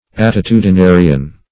Search Result for " attitudinarian" : The Collaborative International Dictionary of English v.0.48: Attitudinarian \At`ti*tu`di*na"ri*an\, n. One who attitudinizes; a posture maker.
attitudinarian.mp3